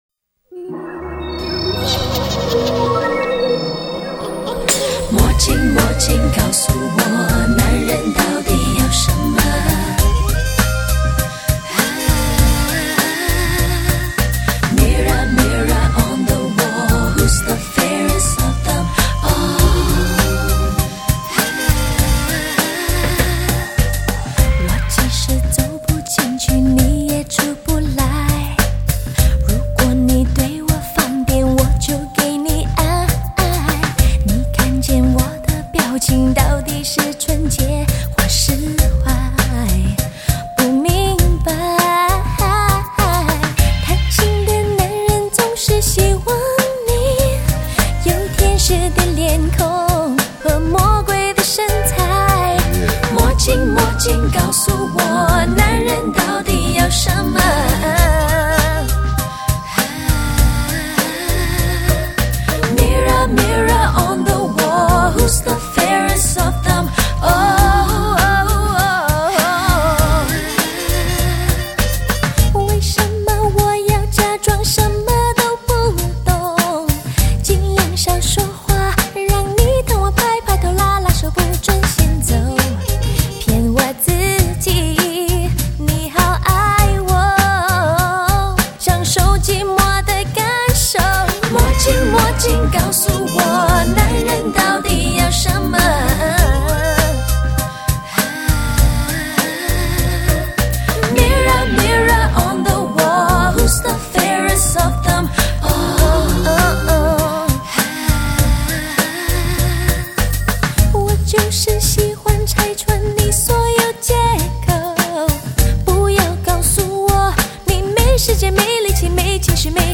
Chinese dance/pop
the groovy tunes